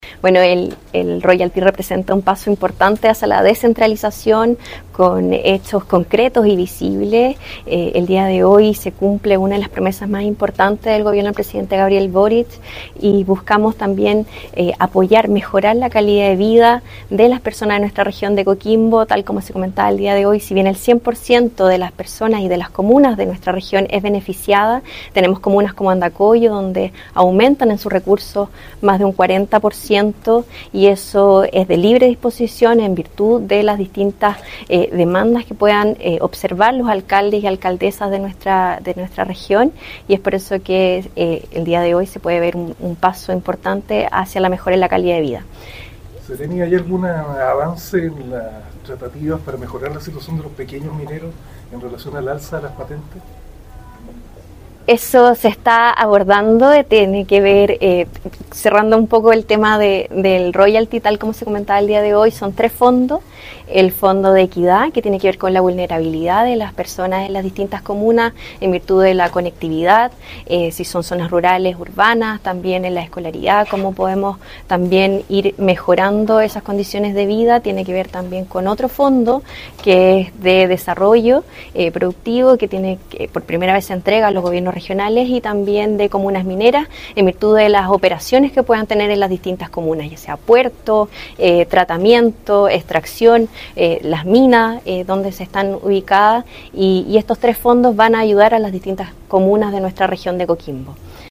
ROYALTY-MINERO-Constanza-Espinosa-Seremi-de-Mineria.mp3